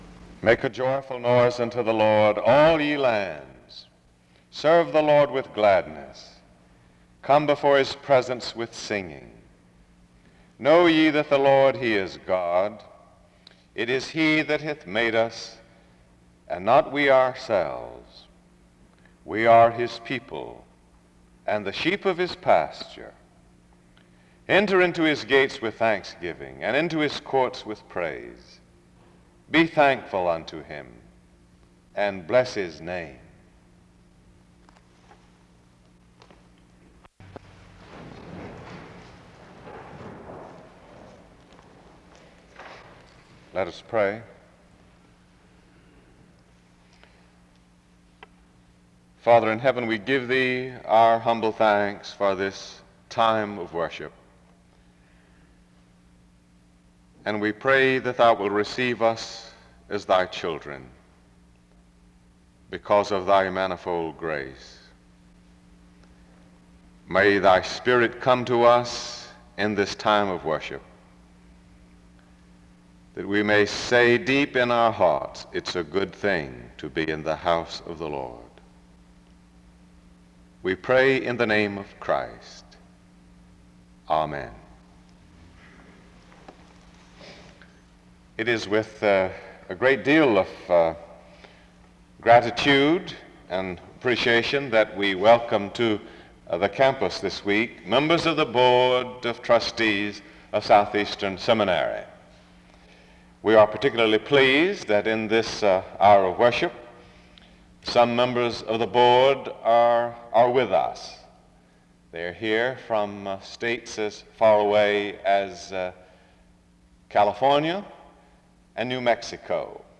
The service begins with a scripture reading and prayer (0:00-1:26).
He explains that missions must start with people recognizing a need (13:35-17:36). He concludes by challenging his audience to contribute to the mission of the Gospel (17:37-23:17). He closes in prayer (23:18-24:46).